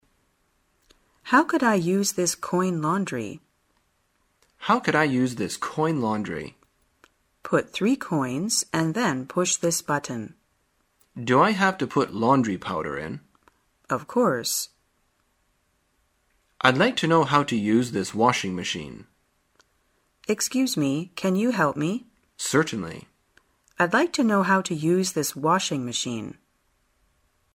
在线英语听力室生活口语天天说 第137期:怎样使用洗衣机的听力文件下载,《生活口语天天说》栏目将日常生活中最常用到的口语句型进行收集和重点讲解。真人发音配字幕帮助英语爱好者们练习听力并进行口语跟读。